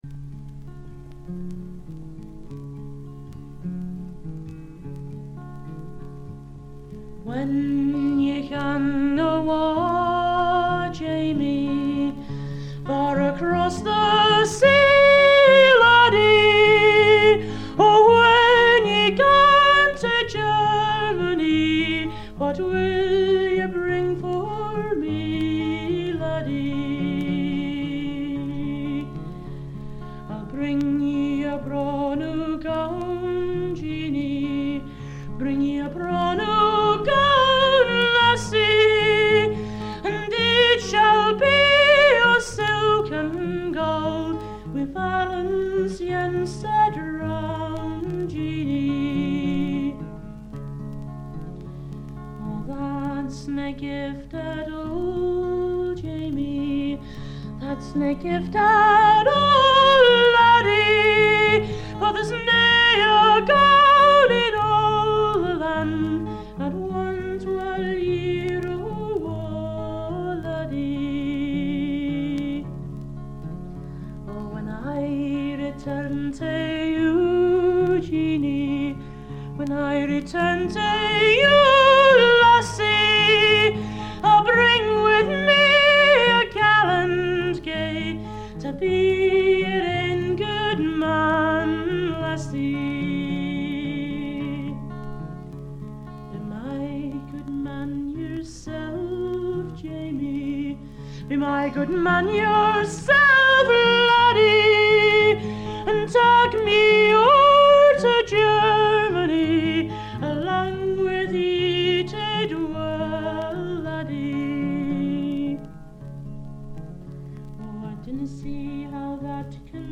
軽微なバックグラウンドノイズにチリプチ少々。
内容はギター弾き語りのライブで、全11曲のうち自作3曲、カヴァー1曲、残りがトラッドという構成です。
試聴曲は現品からの取り込み音源です。